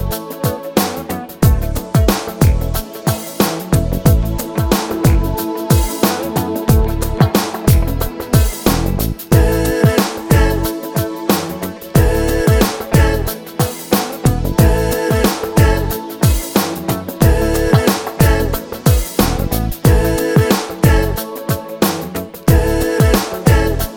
Shortened Version Pop (1990s) 5:12 Buy £1.50